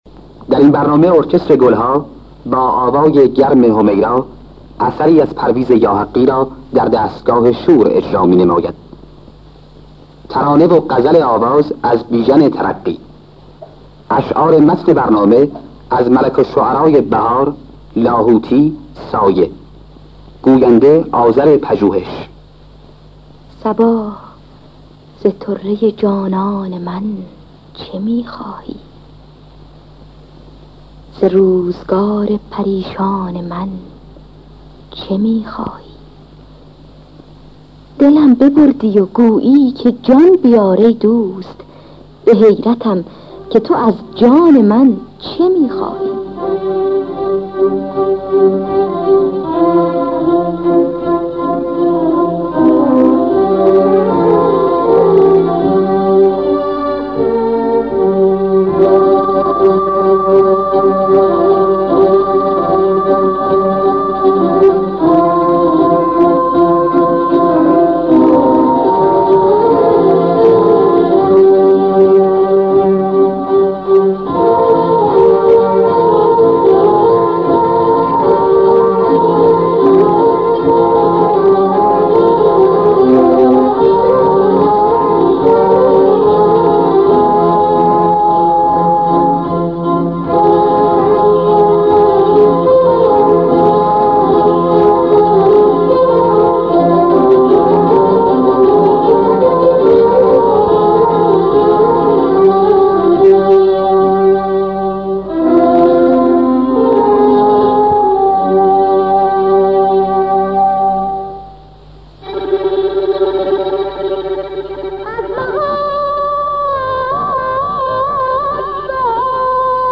دستگاه شور